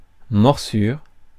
Ääntäminen
IPA : /baɪt/